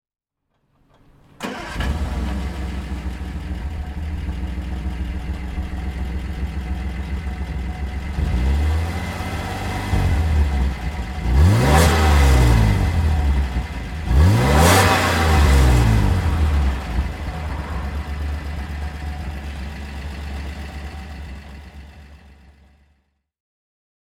Ogle Triplex GTS (1965) - Starten und Leerlauf